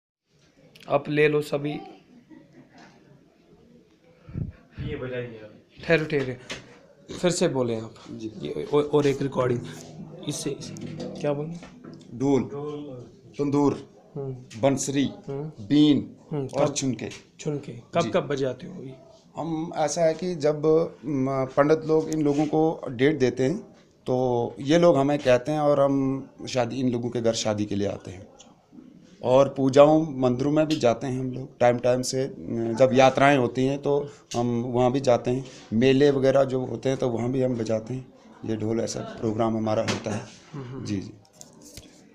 Narrative about the musical instruments used in weddings